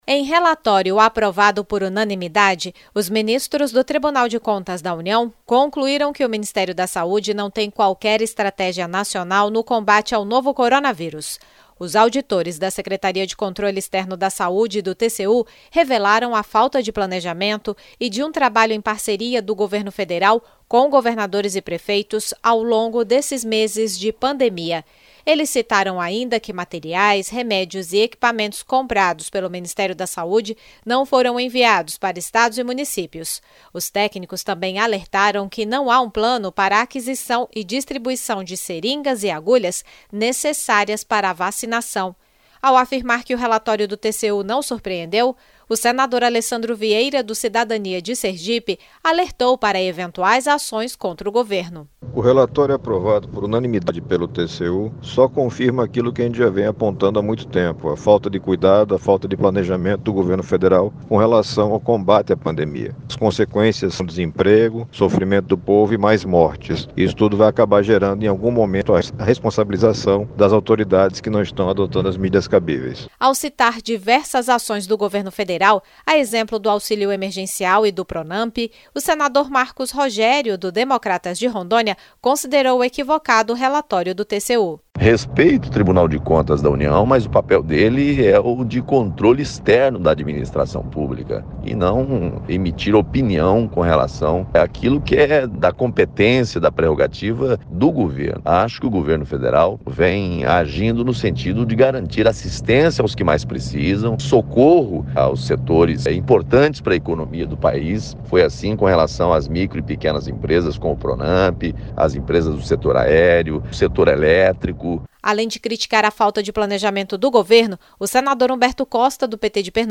Ao comentar o relatório, os senadores Alessandro Vieira (Cidadania-SE) e Humberto Costa (PT-PE) criticaram a gestão de Jair Bolsonaro, enquanto o senador Marcos Rogério (DEM-RO) defendeu o governo federal.